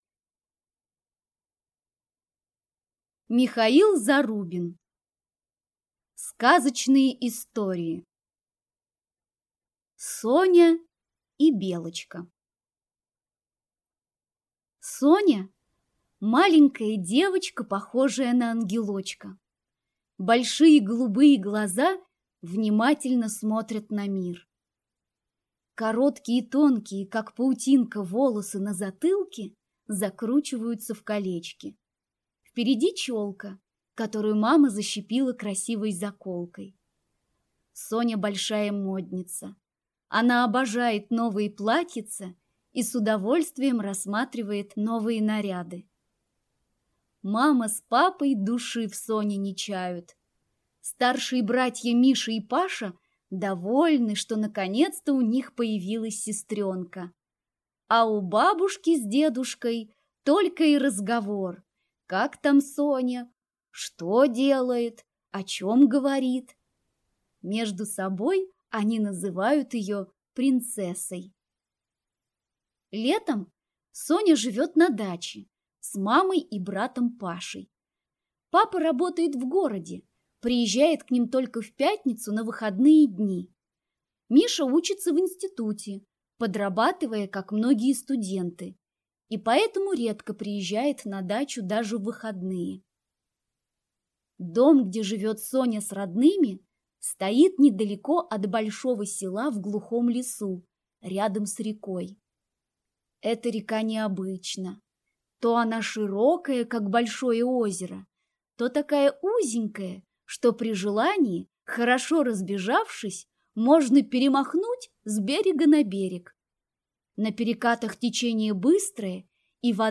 Аудиокнига Сказочные истории | Библиотека аудиокниг